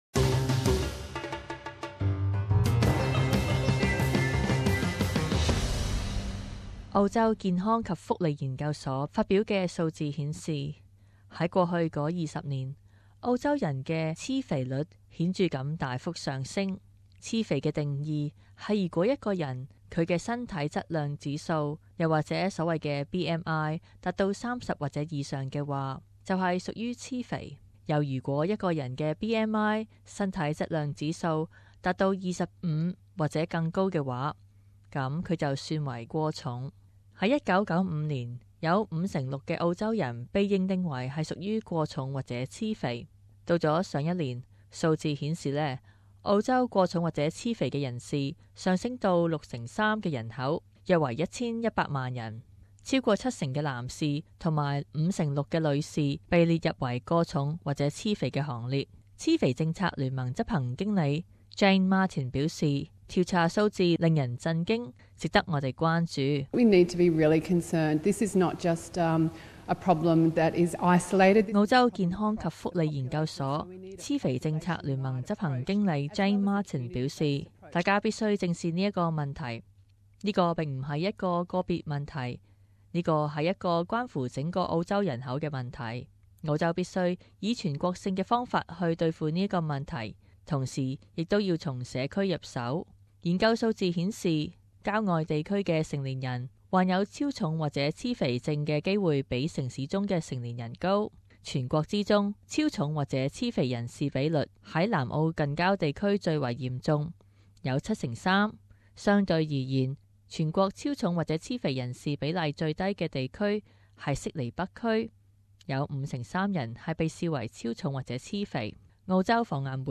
【時事報導】癡肥問題惡化